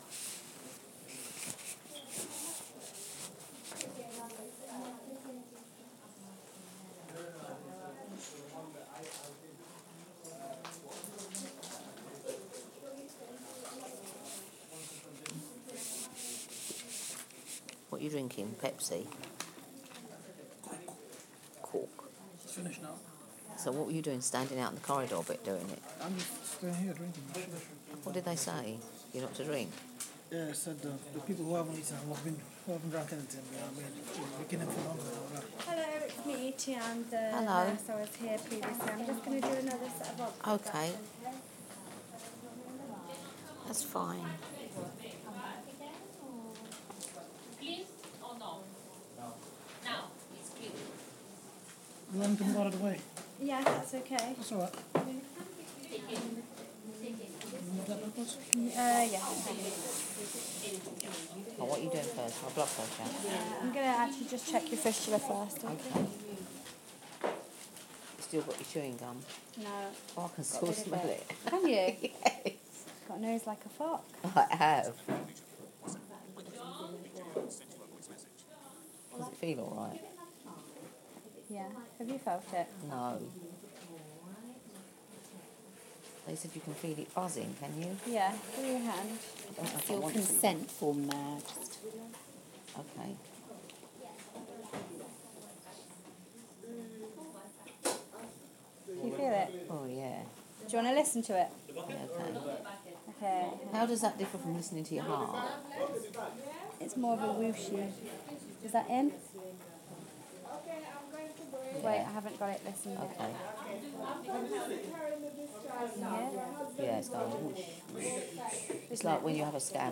Please excuse the muffled part at the beginning but it doesn't last